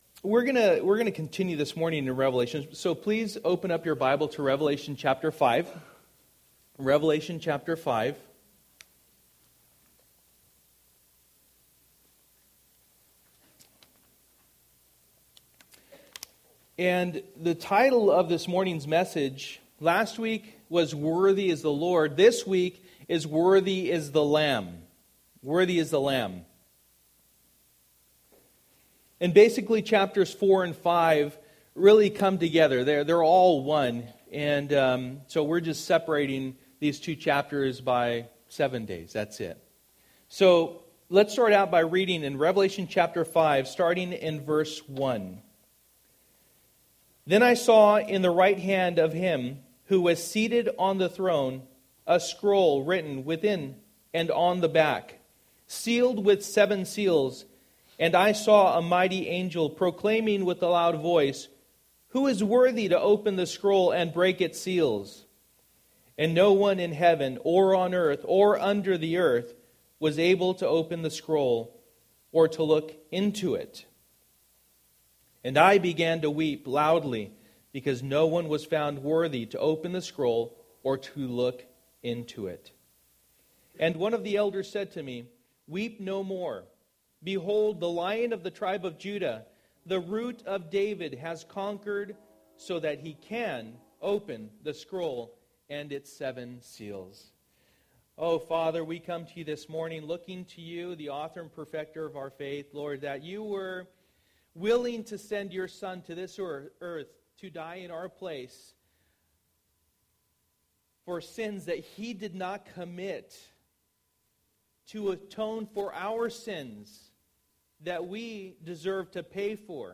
For the Time is Near Passage: Revelation 5:1-14 Service: Sunday Morning %todo_render% Download Files Bulletin « Worthy is the Lord!